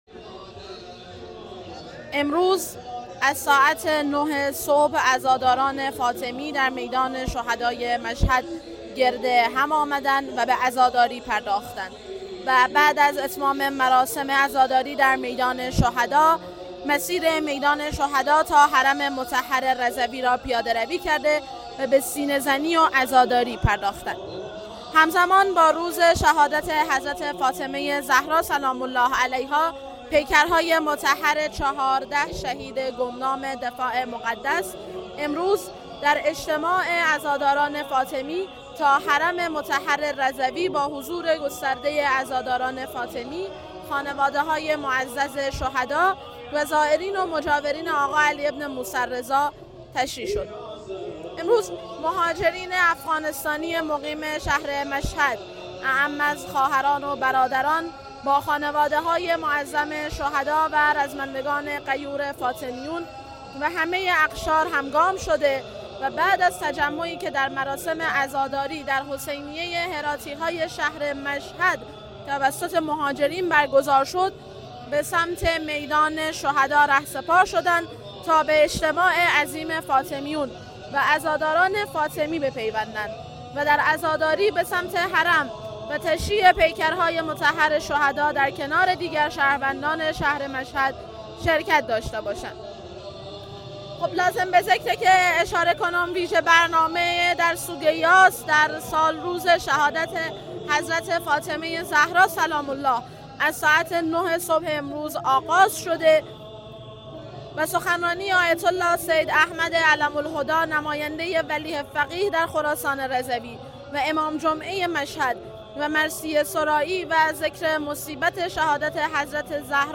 گزارش
عزاداران فاطمی امروز از ساعت 9 صبح با تجمع در میدان شهدای مشهد به سینه زنی و عزاداری پرداختند.